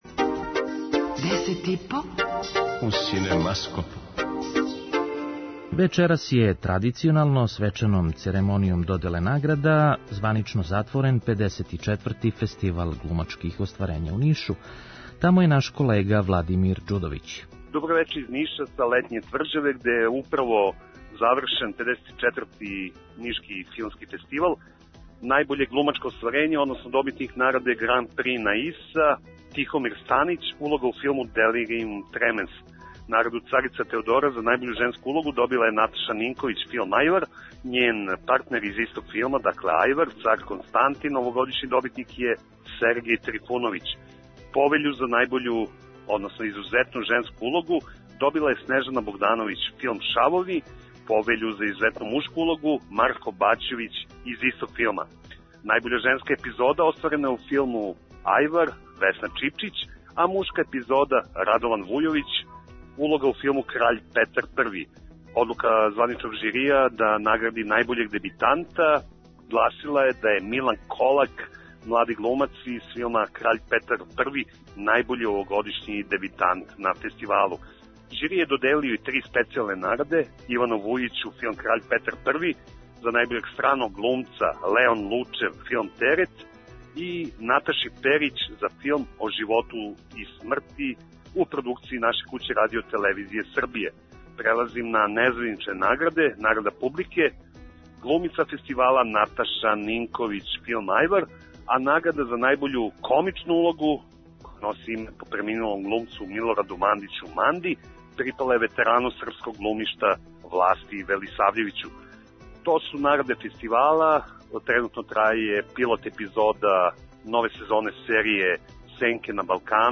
преузми : 5.00 MB Десет и по Autor: Тим аутора Дневни информативни магазин из културе и уметности. Вести, извештаји, гости, представљање нових књига, концерата, фестивала, репортерска јављања са изложби, позоришних и филмских премијера и најактуелнијих културних догађаја.